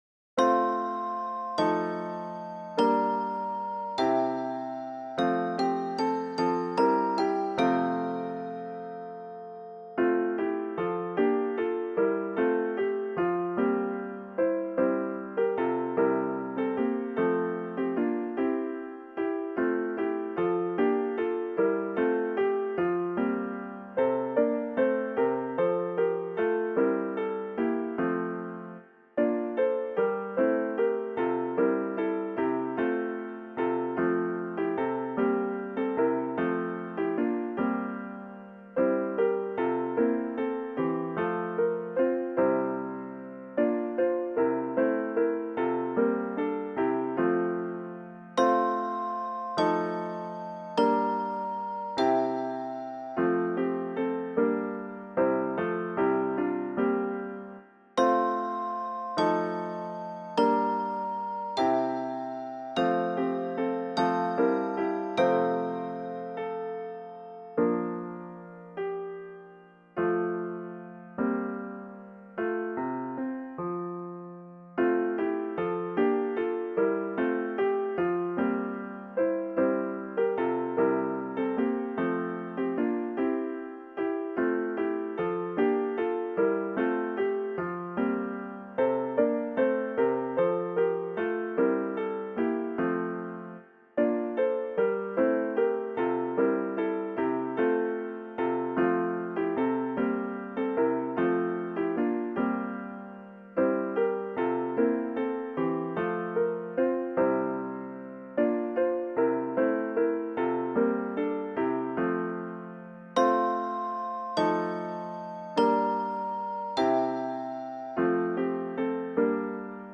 Voicing/Instrumentation: SA , SSA , Celesta , Duet , Hand Bells/Hand Chimes , Young Women Voices